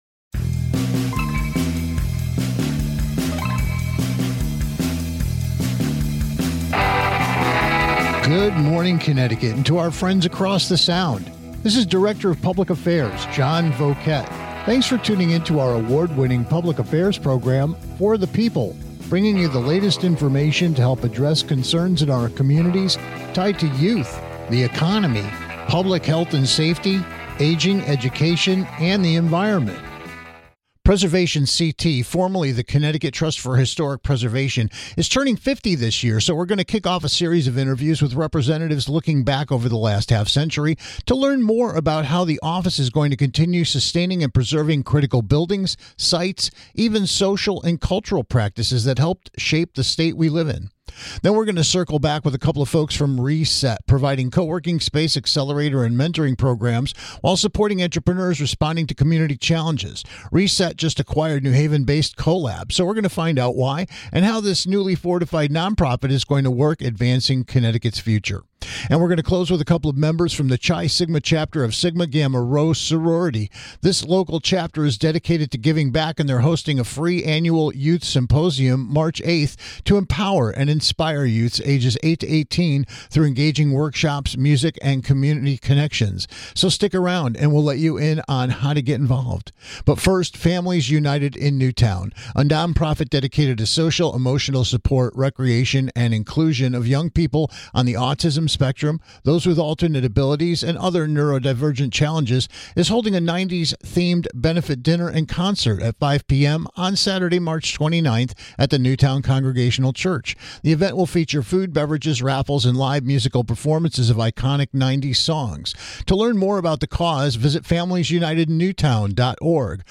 PreservationCT - formerly the Connecticut Trust for Historic Preservation is turning 50 this year, so we'll kick off a series of interviews with representatives to learn more about how they're going to continue sustaining and preserving critical buildings, sites, even social a...